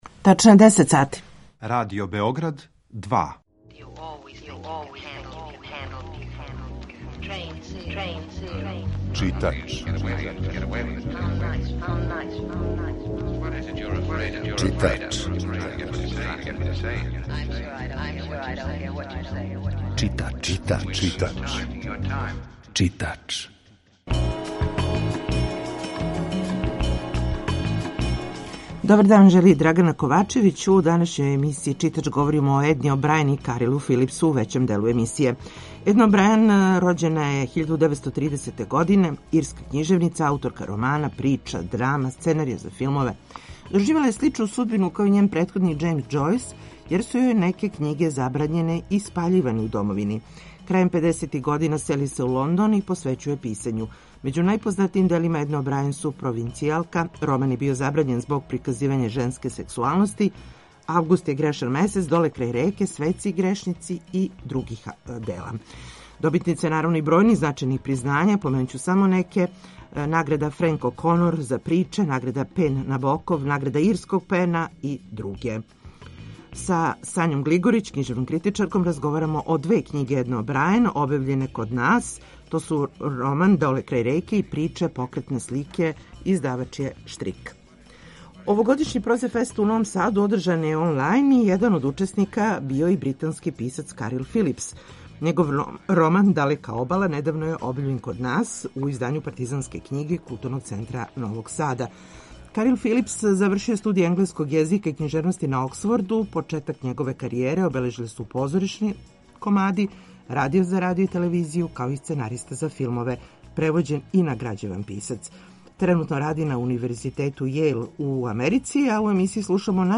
Емисија је колажног типа, али је њена основна концепција – прича о светској књижевности
У емисији слушамо најзанимљивије делове разговора са Карилом Филипсом.